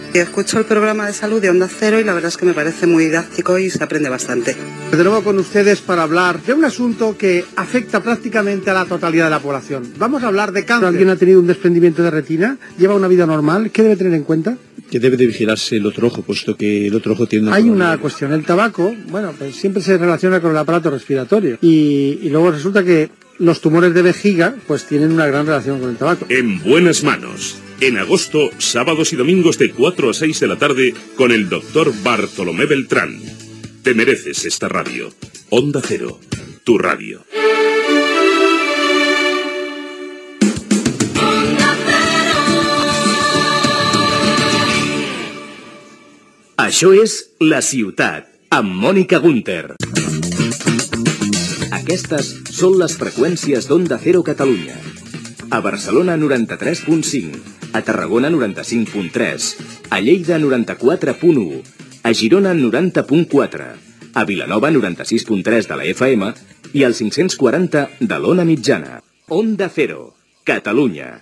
Promoció del programa "En buenas manos", indicatiu, freqüències d'Onda Cero a Catalunya i identificació